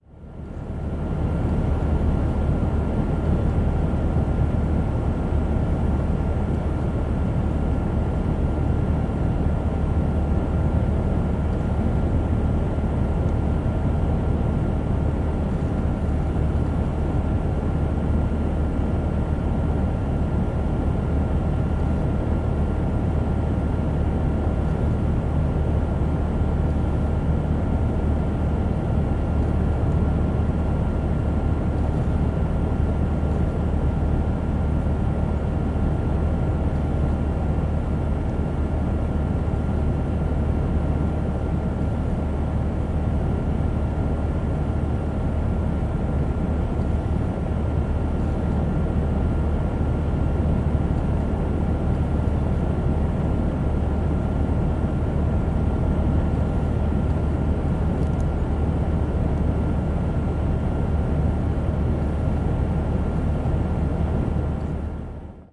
描述：计算机笔记本电脑数据处理关闭短期好India.flac